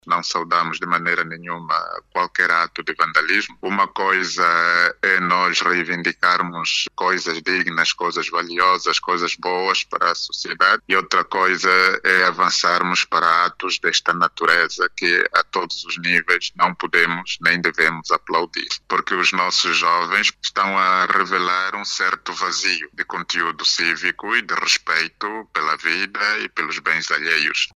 Dom José Manuel Imbamba defende a responsabilização das pessoas envolvidas nestes actos.